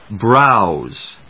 /brάʊz(米国英語)/